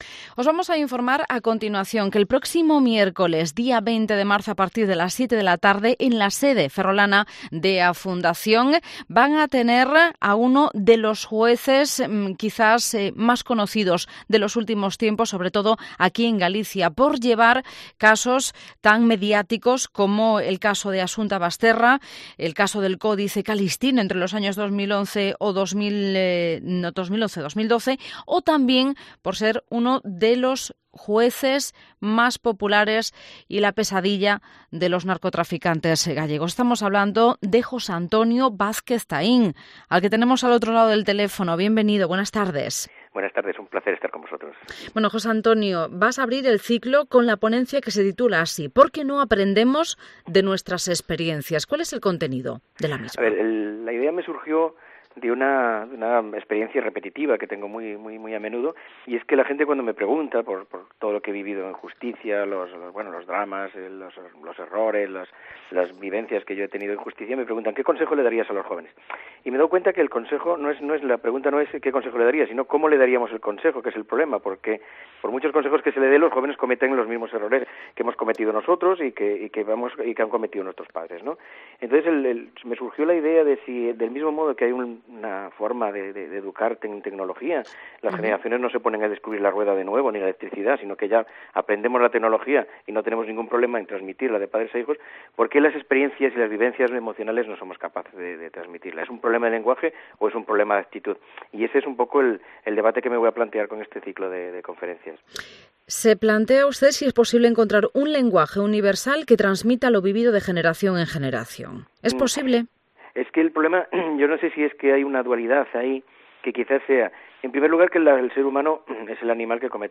El juez Vázquez Taín estuvo el pasado lunes en el "Mediodía Cope Ferrol"